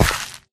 step / gravel4